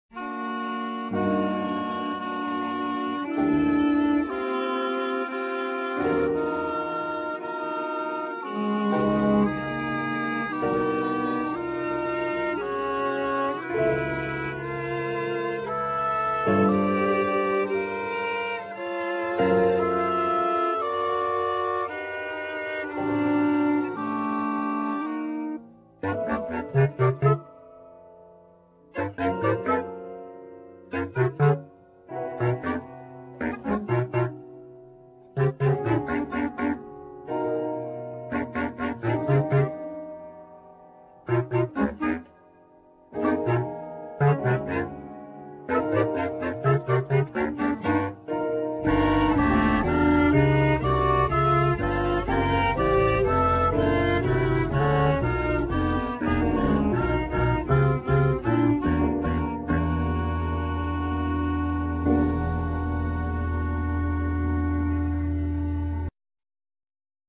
Basson, Oboe
Drums, Percussions, Radio
Bass, Guitar, Prepared guitar
Organ, Piano, Bass clarinet, Altsax, Xylophone, Percussions
Cello, electric cello, Voice
Piano, Synthsizer